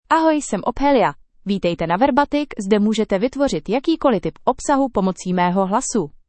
OpheliaFemale Czech AI voice
Ophelia is a female AI voice for Czech (Czech Republic).
Voice sample
Listen to Ophelia's female Czech voice.
Female
Ophelia delivers clear pronunciation with authentic Czech Republic Czech intonation, making your content sound professionally produced.